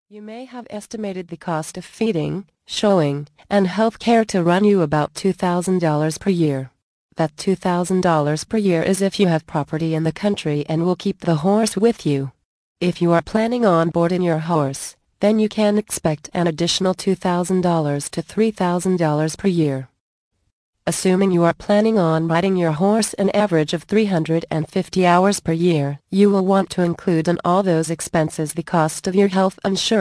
Beginners guide to Horse Training audio book + FREE Gift